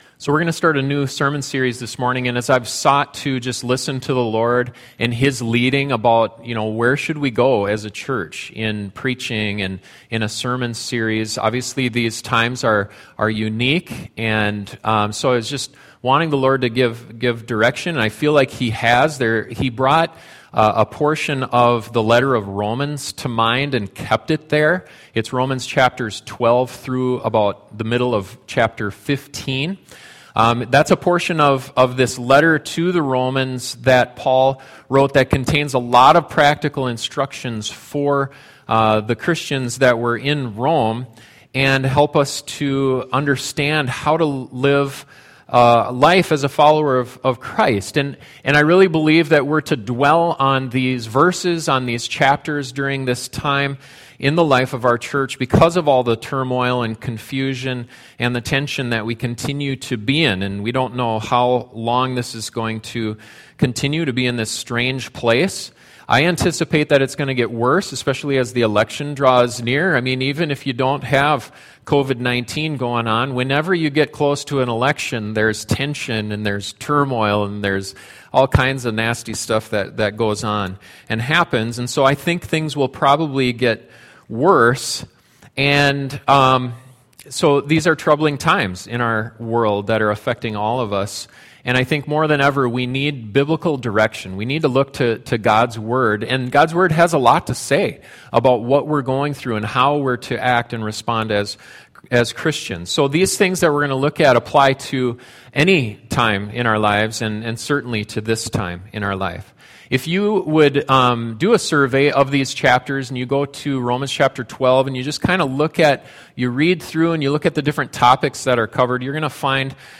The first sermon in a new sermon series from Romans chapters 12-15 to provide instruction for living in difficult times.